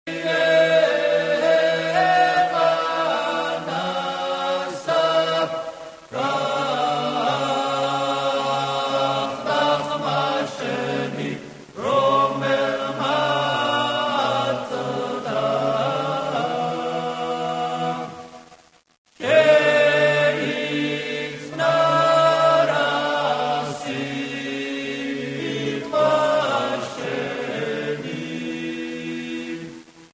Traditional songs of Georgia